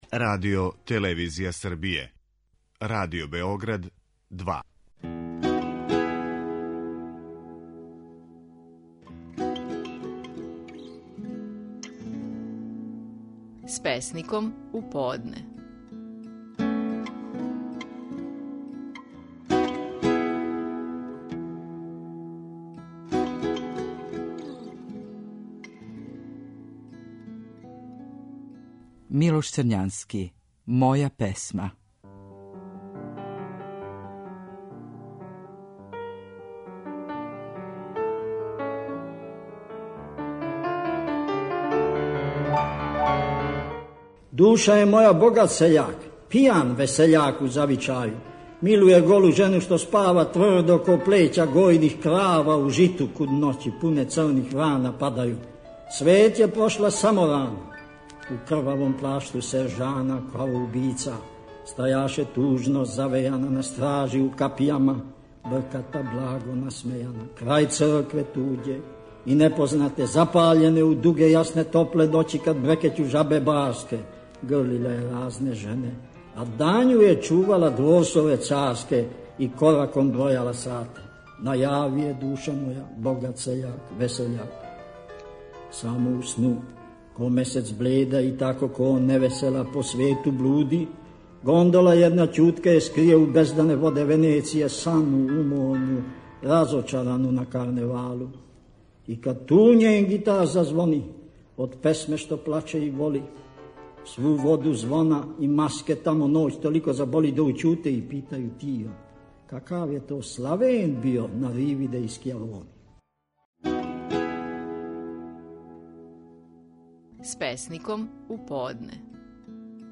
Стихови наших најпознатијих песника, у интерпретацији аутора.
„Моја песма" је назив стихова Милоша Црњанског које ћемо чути у његовој интерпретацији.